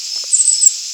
Very high-pitched contact calls in wind.
chickadee_contact_call564.wav